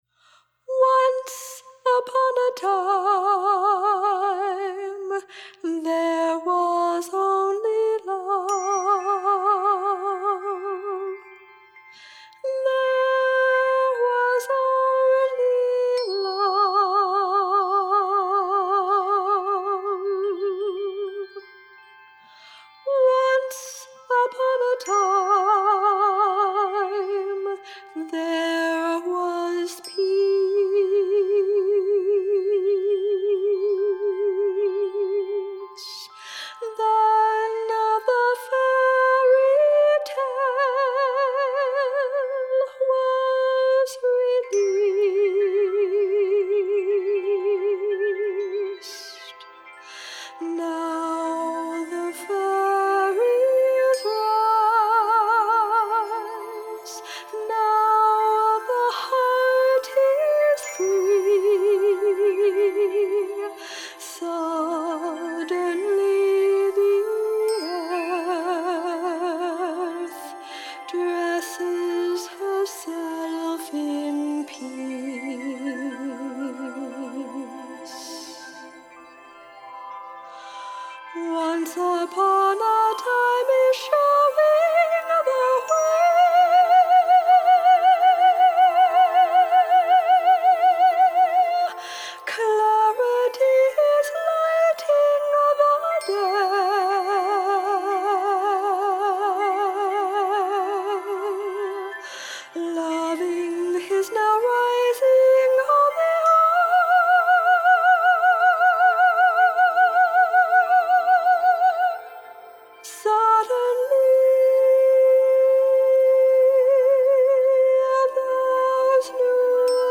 Tingshas
Soundscape